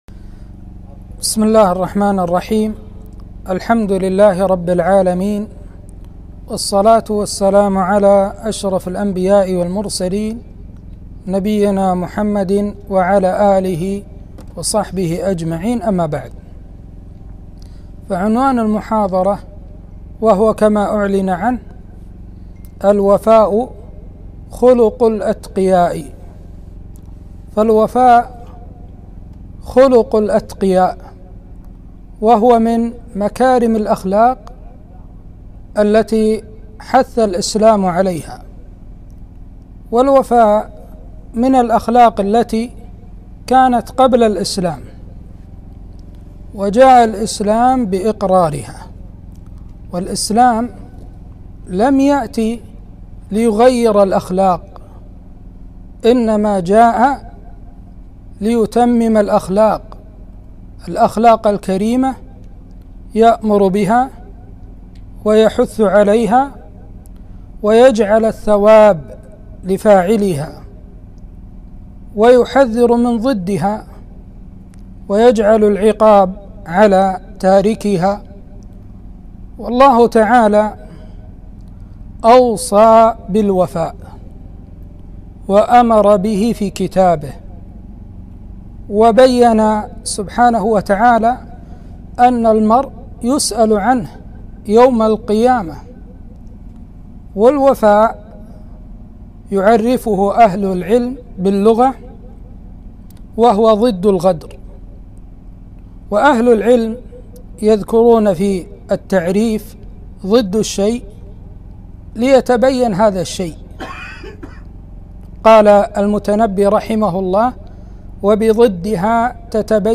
محاضرة - الوفاء خلق الأتقياء